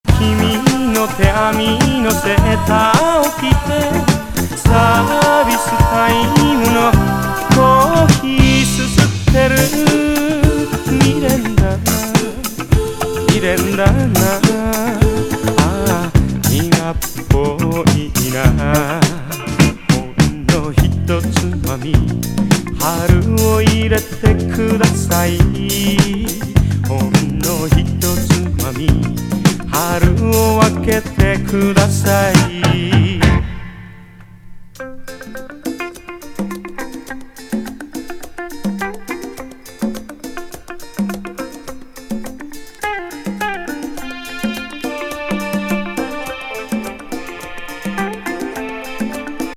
グルーヴィー・フォーキー歌謡!